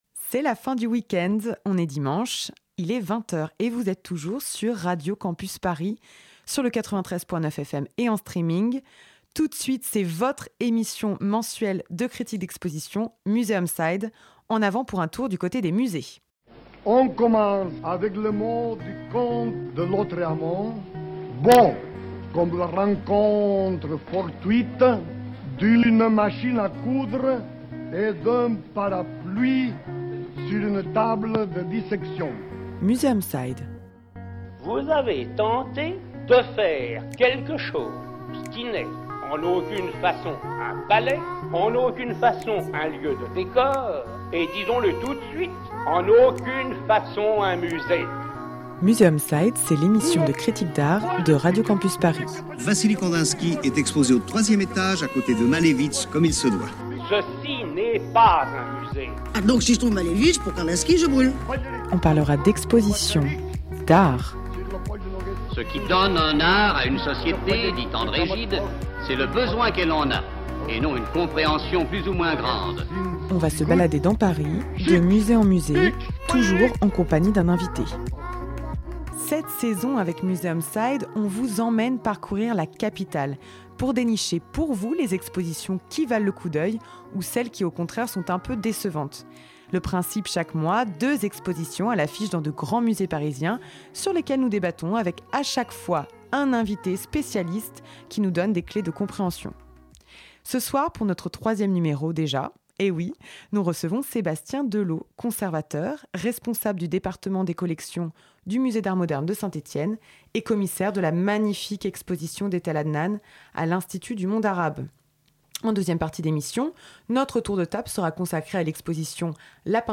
Cette saison, avec Museum side, on vous emmène parcourir la capitale pour dénicher pour vous les expositions qui valent le coup d’œil ou celles qui au contraire sont un peu décevantes. Le principe chaque mois : deux expositions à l'affiche dans de grands musées parisiens sur lesquelles nous débattons avec à chaque fois un invité spécialiste qui nous donne des clés de compréhension.